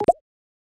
Bamboo Pop v2 Notification2.wav